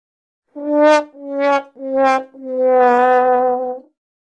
Wa Wa Wa Waa Sound Effect - Botão de Efeito Sonoro
Toque o icônico botão de som Wa Wa Wa Waa Sound Effect para seu meme soundboard!